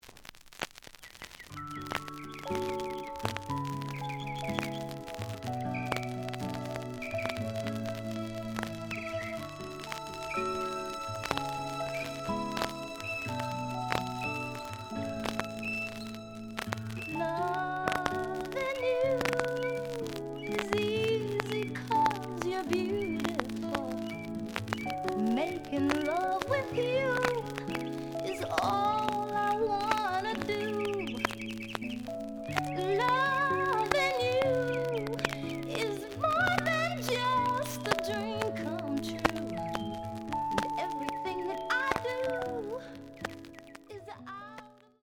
The audio sample is recorded from the actual item.
●Genre: Soul, 70's Soul
Noticeable noise on both sides due to scratches.)